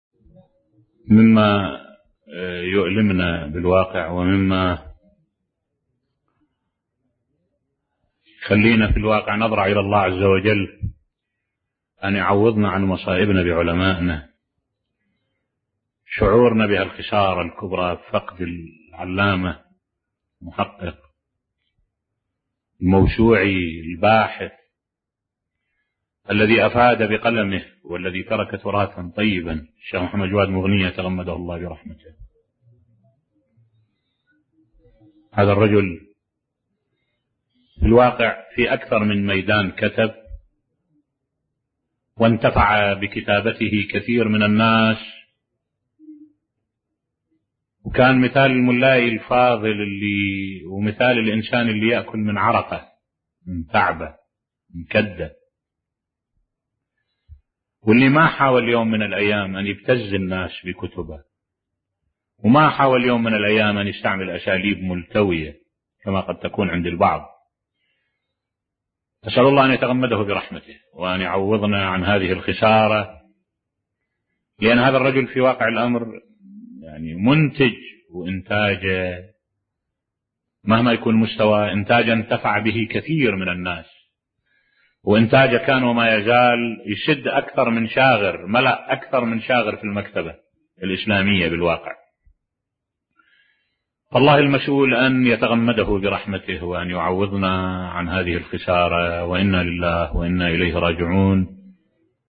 ملف صوتی تأبين الشيخ محمد جواد مغنية قدس سره بصوت الشيخ الدكتور أحمد الوائلي